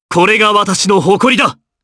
Clause-Vox_Victory_jp.wav